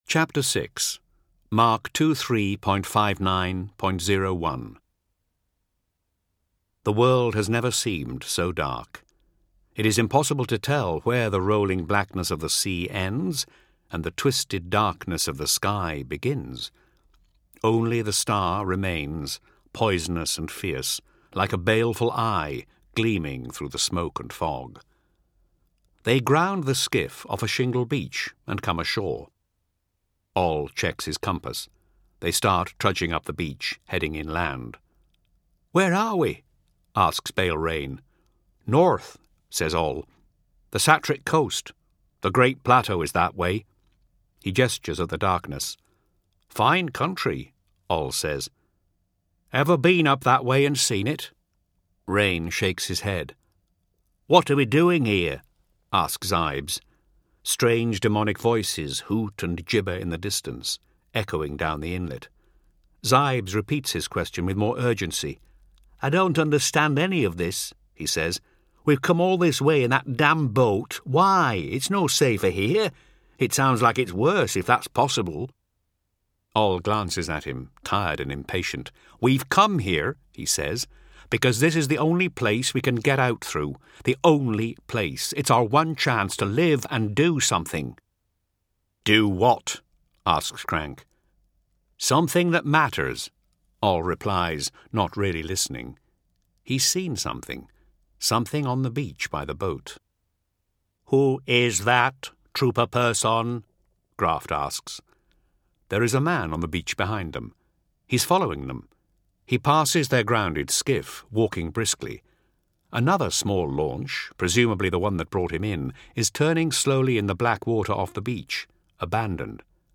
Games/MothTrove/Black Library/Horus Heresy/Audiobooks/The Complete Main Series/HH 11-20/19